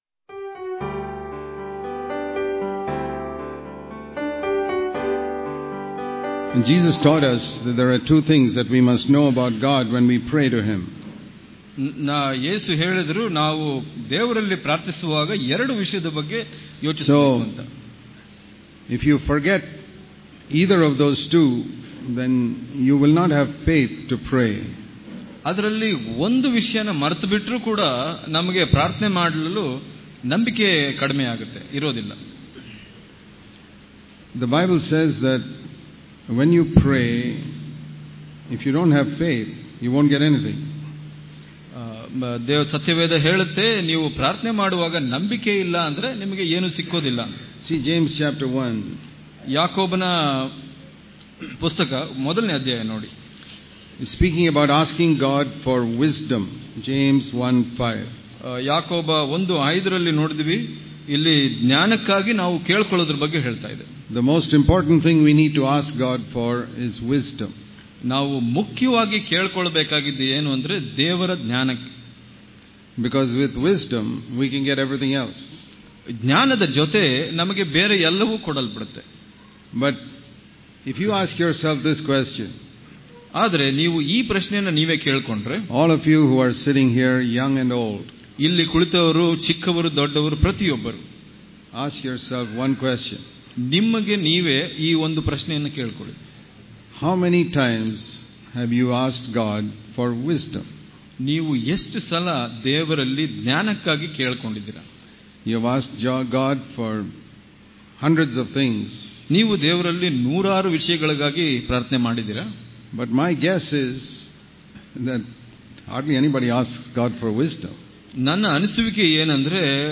April 29 | Kannada Daily Devotion | It Is Important To Set Things Right And Have Faith Before We Pray Daily Devotions